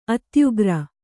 ♪ atyugra